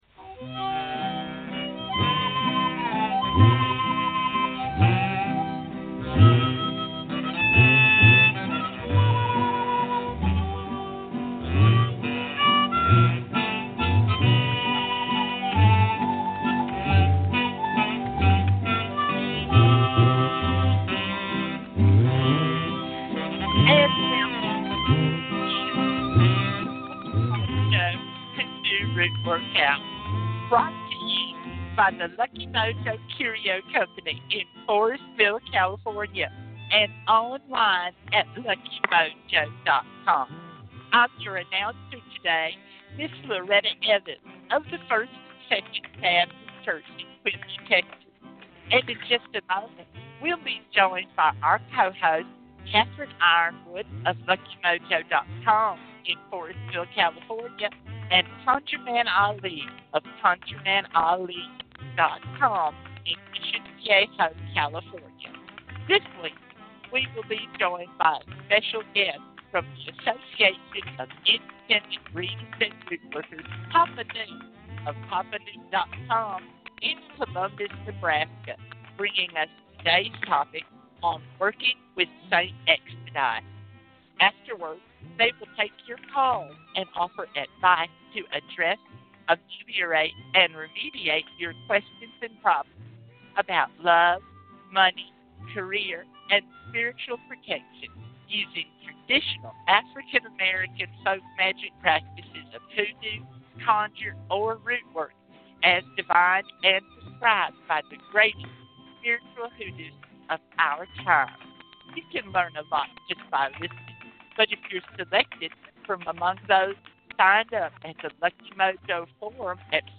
present a tutorial on Working with Saint Expedite and provide 90 minutes of free readings, free spells, and conjure consultations, giving listeners an education in African-American folk magic.